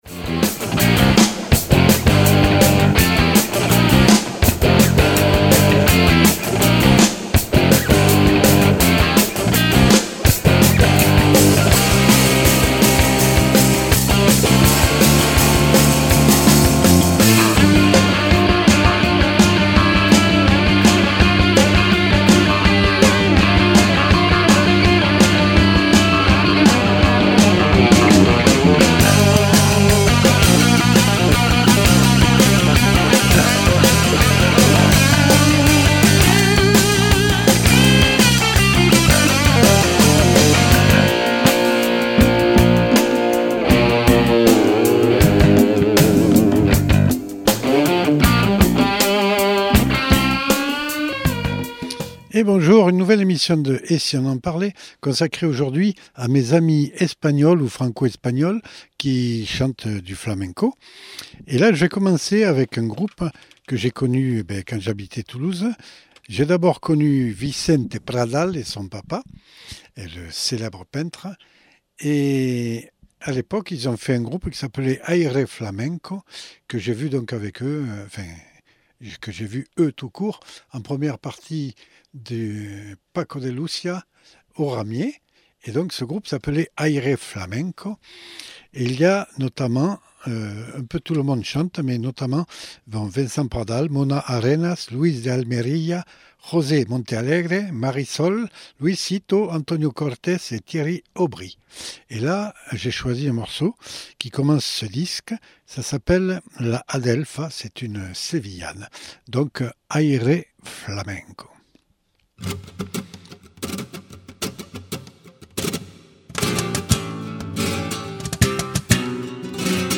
Invité(s) : Les musiciens espagnols Toulousains et autres